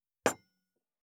228,テーブル等に物を置く,食器,グラス,コップ,工具,小物,雑貨,コトン,トン,ゴト,ポン,ガシャン,ドスン,ストン,カチ,タン,バタン,スッ,サッ,コン,
コップ効果音物を置く